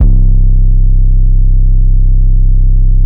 BWB WAV R US 808( (15).wav